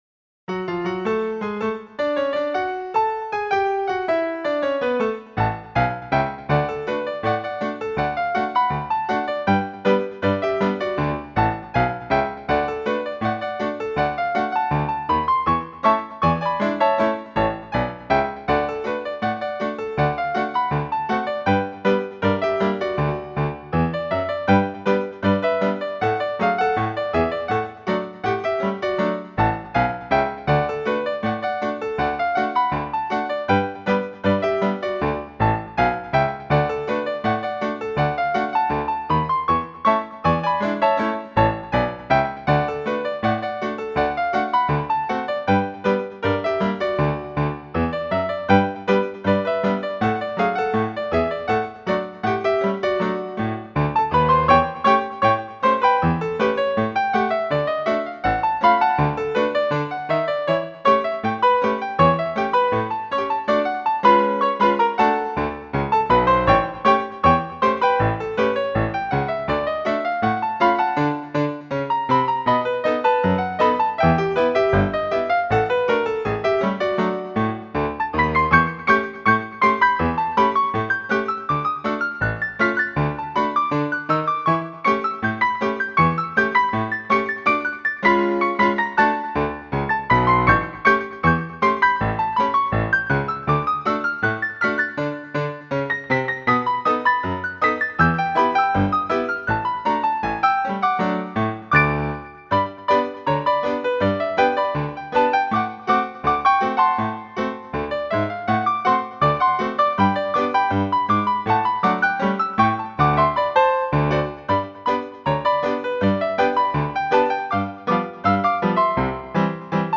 Description: A happy ragtime song
Genre: Ragtime